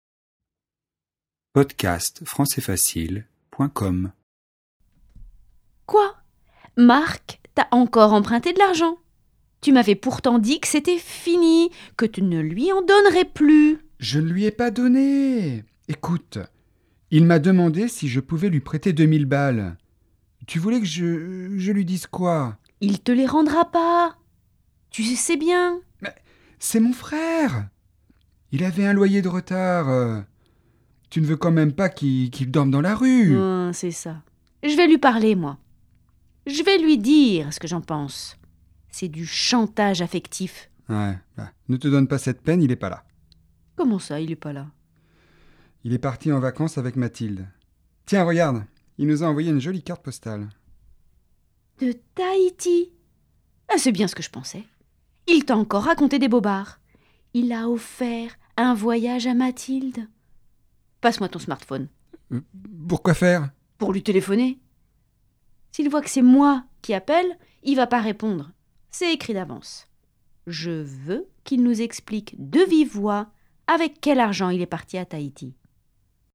🔷 Dialogue :